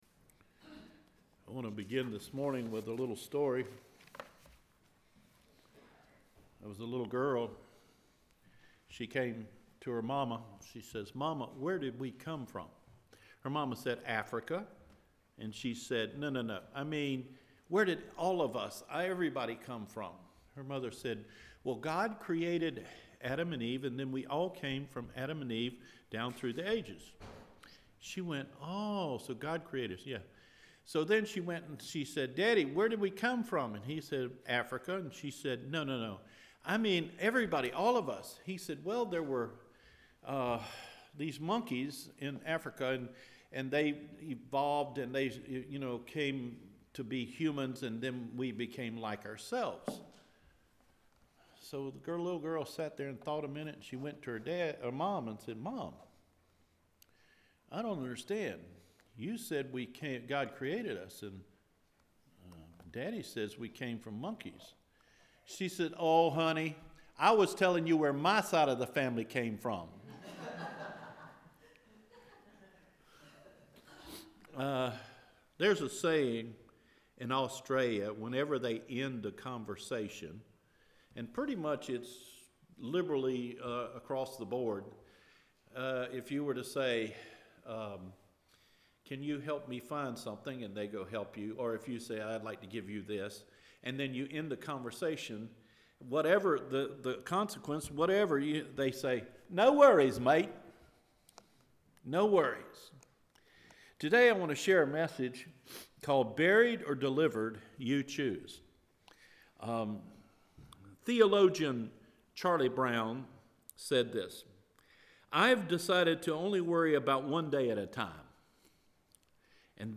Buried or Delivered – January 21 Sermon